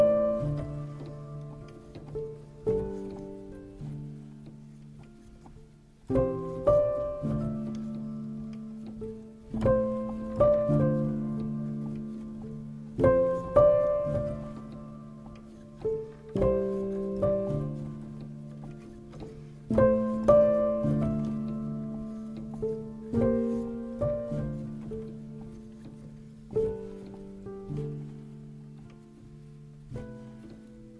paysages ambient
les éléments percussifs et électroniques de son travail
créer son art sur scène